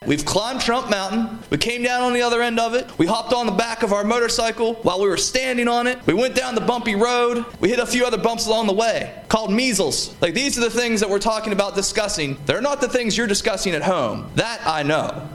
With just 3 days left in the West Virginia Legislature, the Democratic minority is voicing frustration with lack of focus on bills that affect West Virginia residents.  In a weekly press briefing, House Minority Whip Shawn Fluharty, mocking bills that have passed, says very little real work has been accomplished before the final adjournment scheduled for midnight April 12th…